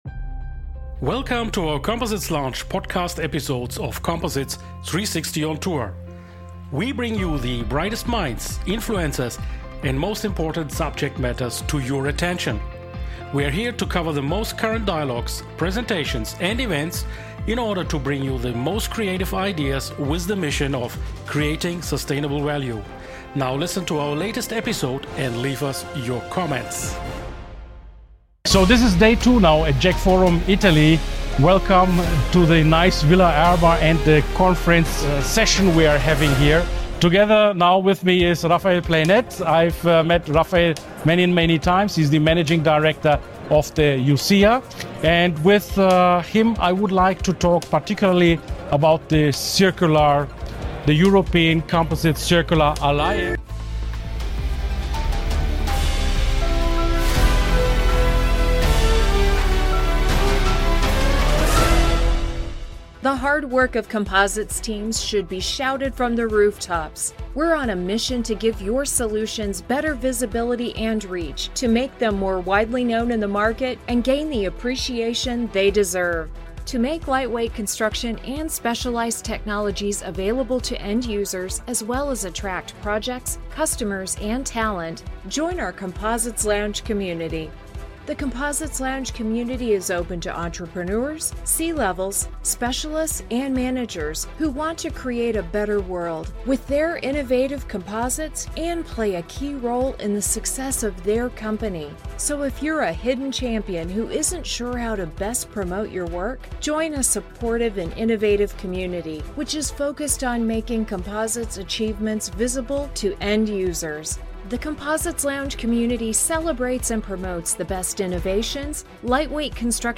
At the stunning Villa Erba during JEC Forum Italy